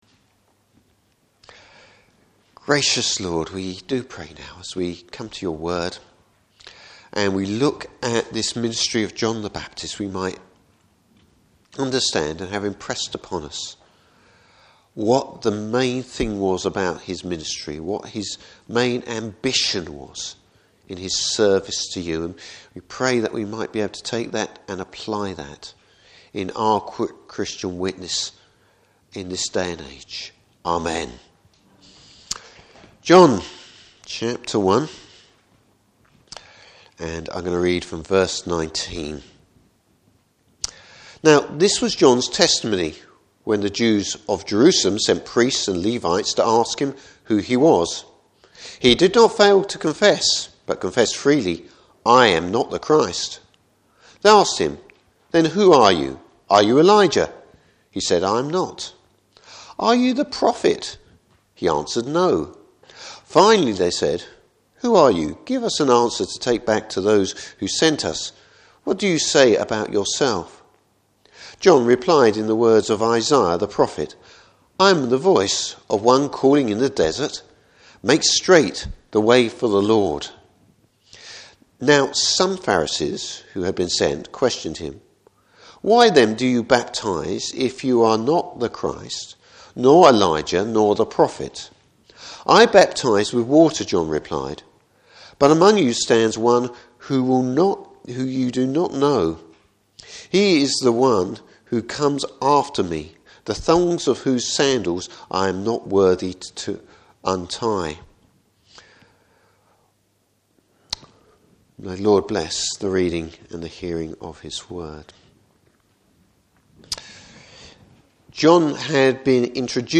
Service Type: Morning Service John’s role in Christ’s coming.